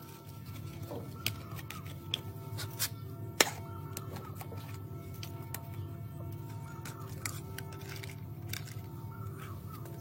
Kling-Audio-Eval / Human sounds /Hands /audio /18340.wav